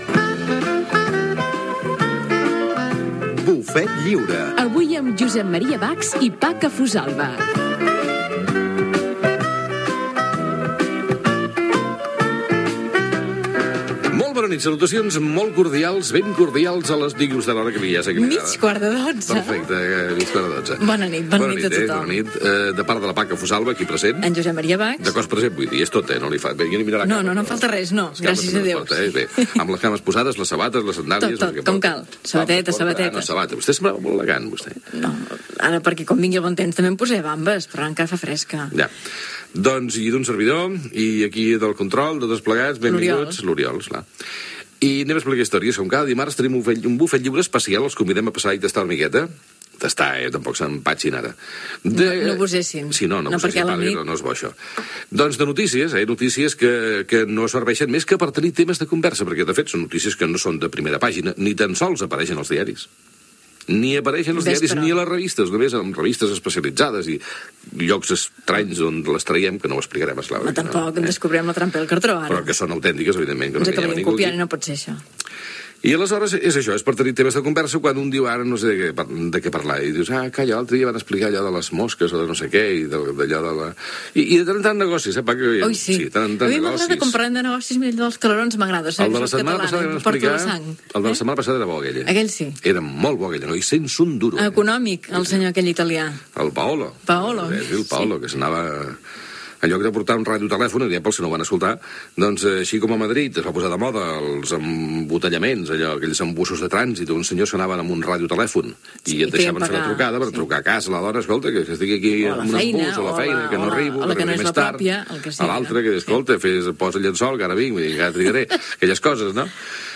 Careta del programa, hora, equip del programa, record a una notícia curiosa donada la setmana passada, avenç de continguts, tema musical
Entreteniment